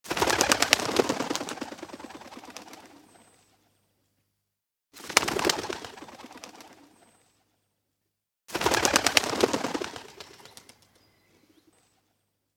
the-sound-of-flapping-birds